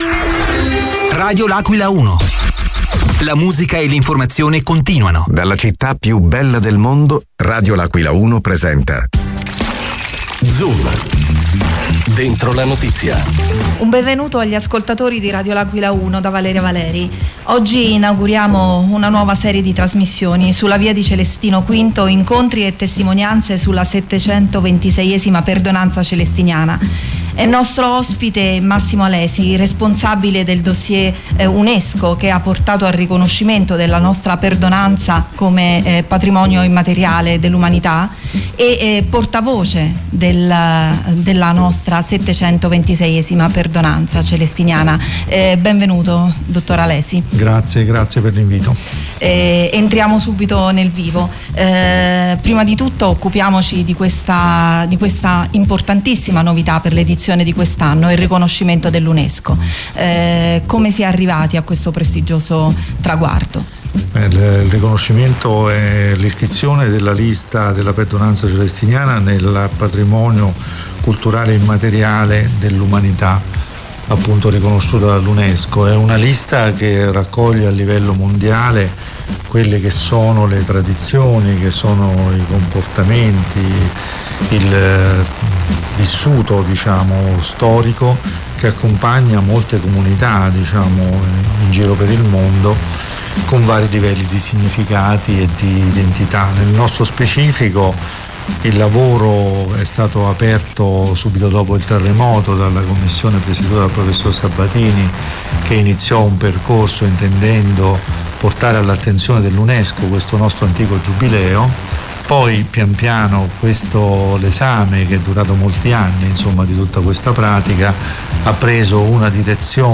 è stato ospite negli studi di Radio L’Aquila 1